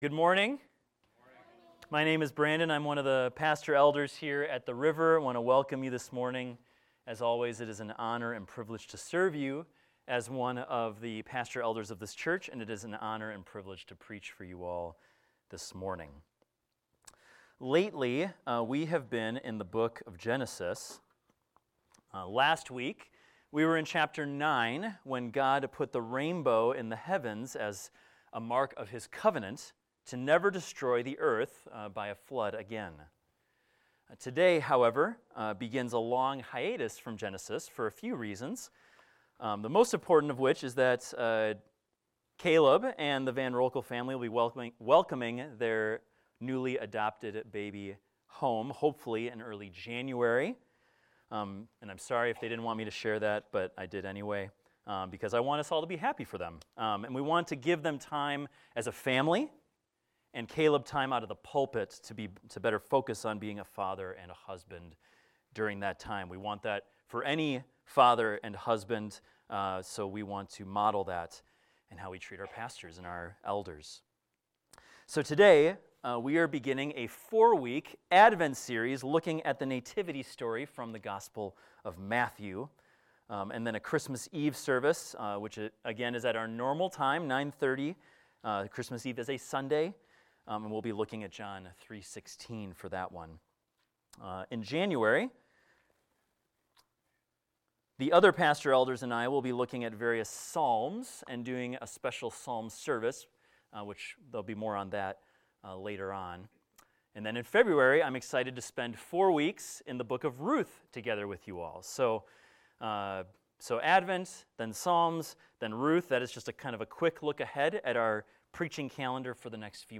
This is a sermon on the Origin of Jesus, looking at His genealogy from Matthew.